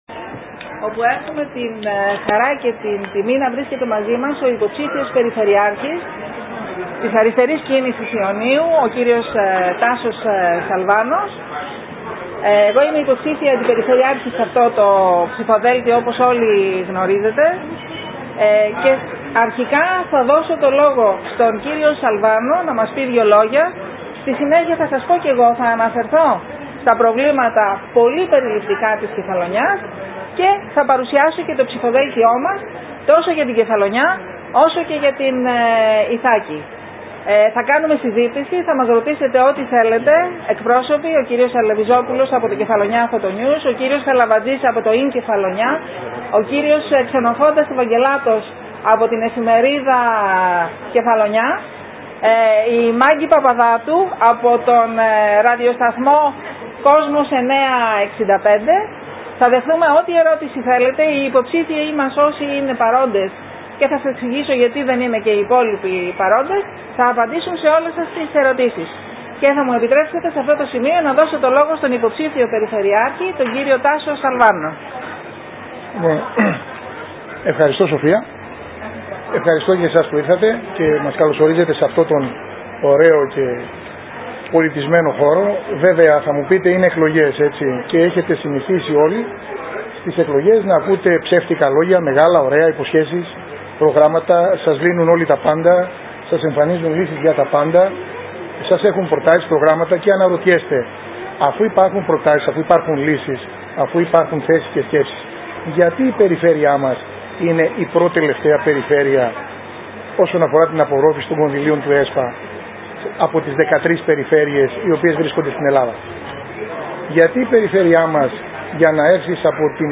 Συνέντευξη τύπου
στο χώρο του καφέ Haagen-Dazs